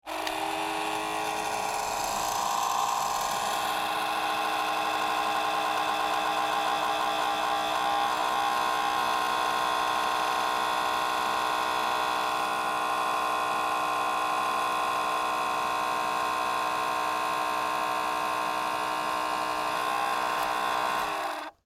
Type 466, Krups hair dryer